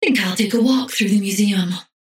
Paradox voice line - Think I'll take a walk through the museum.